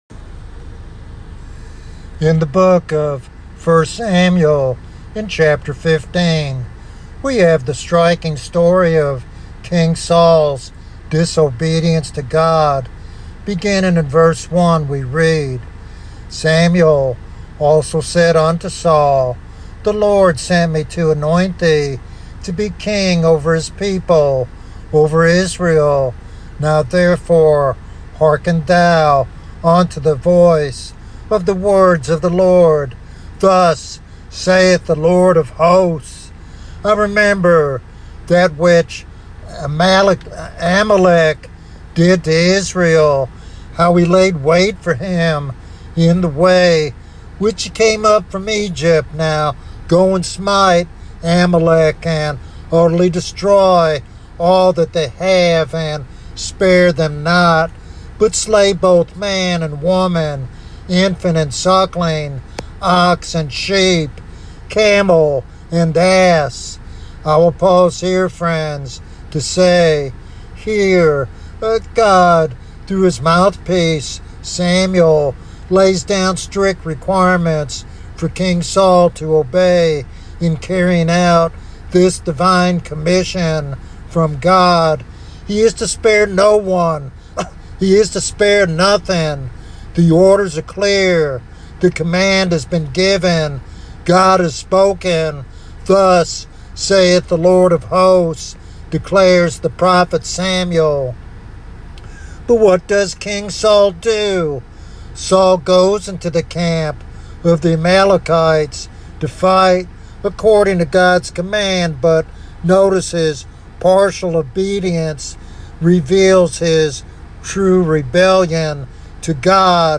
In this prophetic sermon